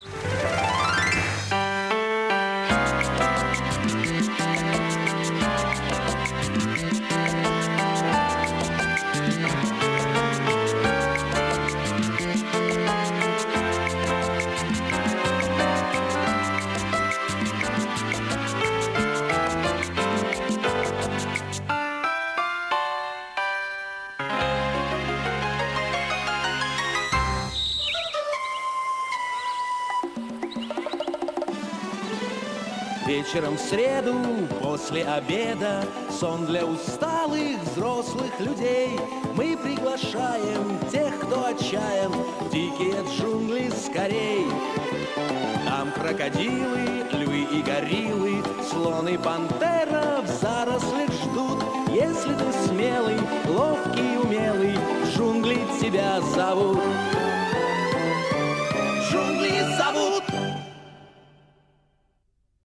Музыкальное завершение